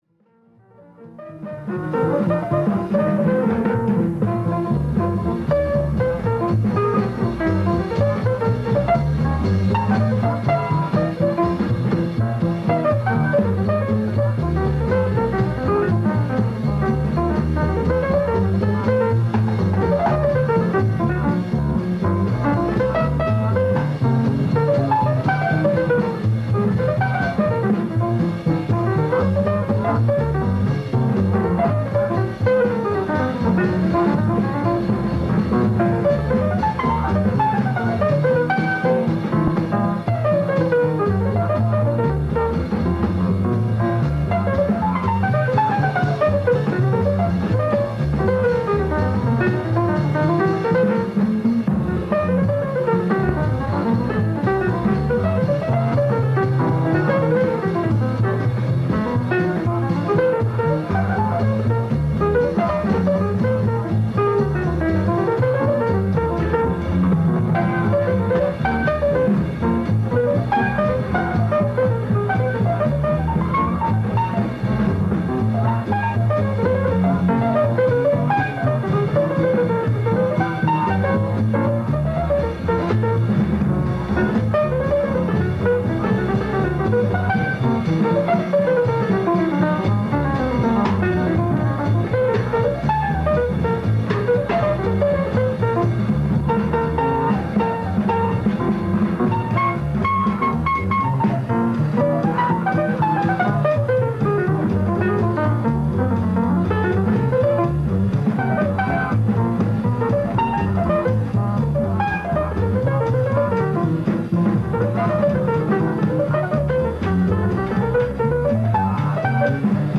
bebop
He swings so hard, all the time.